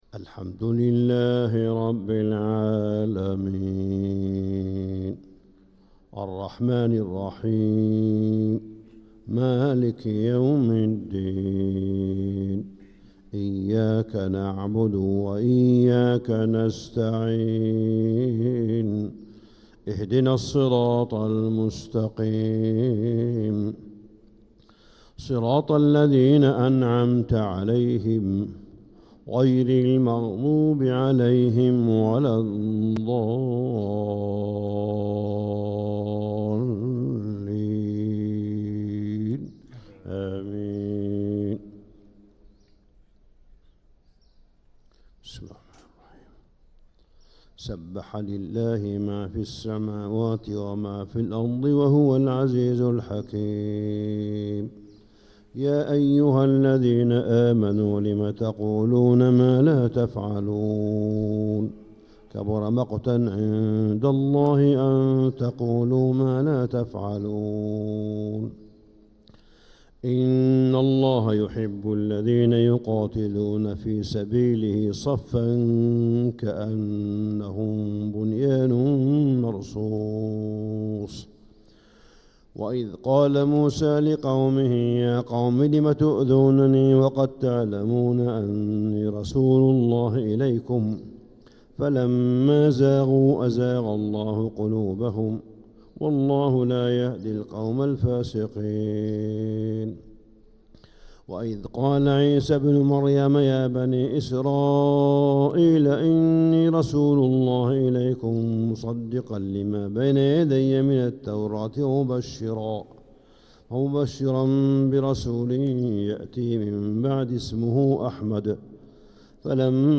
Reciter: Shiekh Salih bin Abdullah bin Hameed